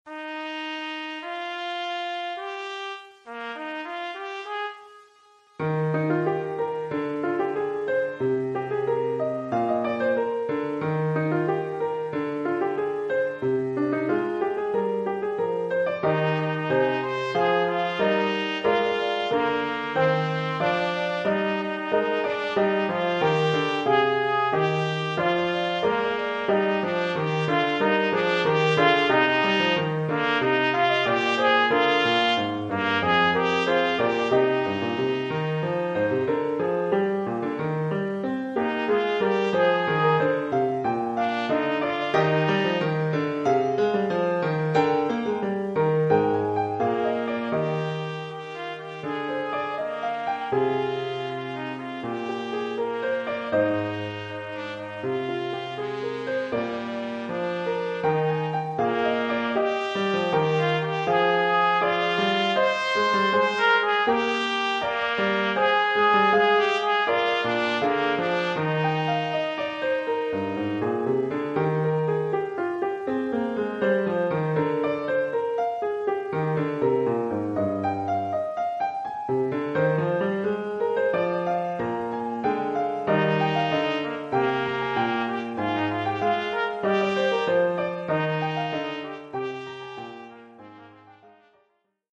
Formule instrumentale : Trompette et piano
Oeuvre pour trompette ou cornet